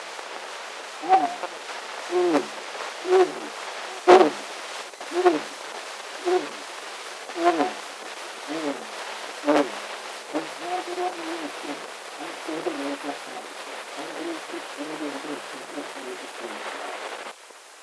Eftersom de endast haft tillgång till fotostatkopior av medfarna fotografiska papperskopior, är det si och så med kvalitén på det återskapade ljudet.
Men – det låter, och somligt går att uppfatta.
En av skivorna (nr 3), innehåller 17 sekunder ljud. Först hörs en nedräkning, förmodligen på engelska, därefter de första raderna av Esaias Tegnérs dikt Karl XII: